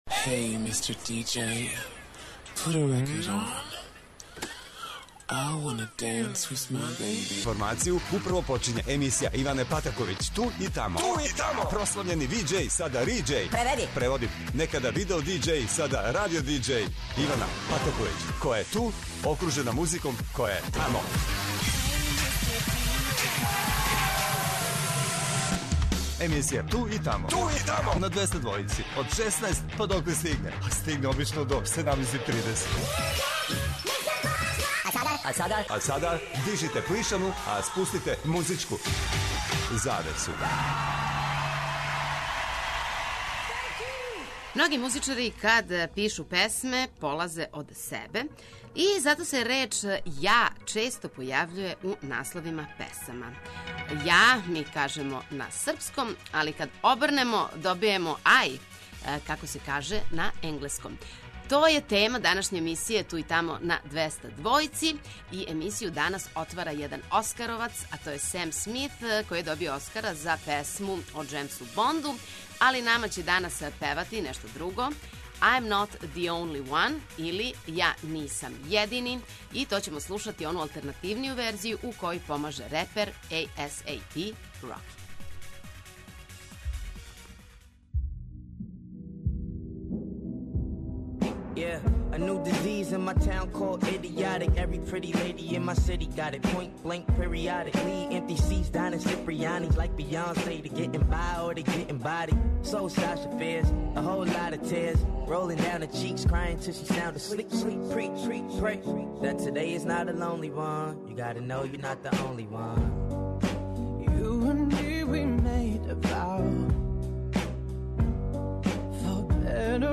Слушаоци у сваком тренутку могу да се јаве и дају својe предлогe на задату тему...